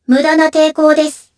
Kara-Vox_Skill5_jp.wav